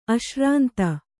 ♪ aśrānta